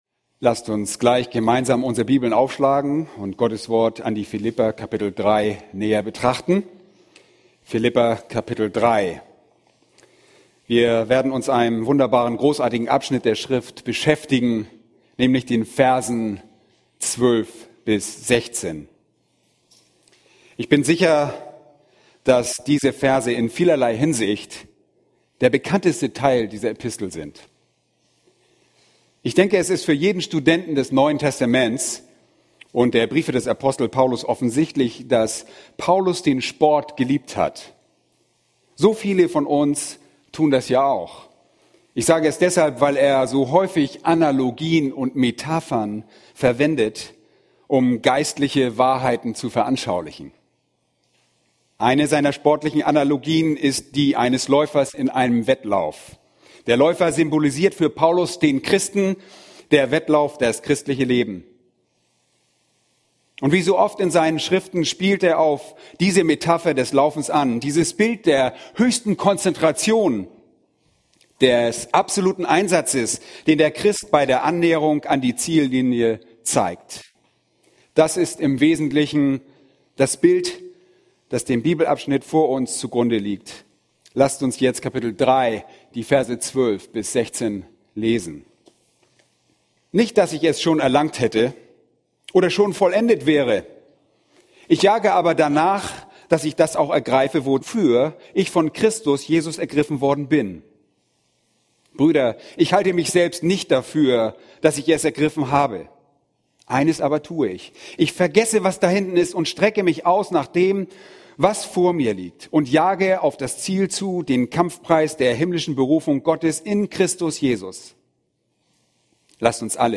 ___ * Diese adaptierte Predigtserie von John MacArthur ist genehmigt durch "Grace to You".